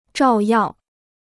照耀 (zhào yào): to shine; to illuminate.